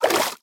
swim4.mp3